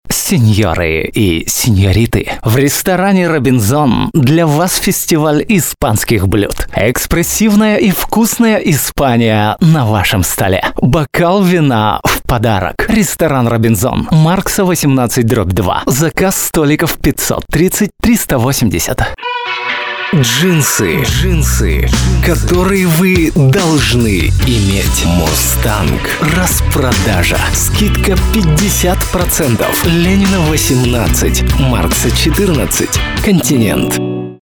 микрофон Rode NT 2 , преамп DIGILAB Ginger SPM-100,ART MDC2001 Stereo master Dynamics controller, M-AUDIO Delta Audiophile 192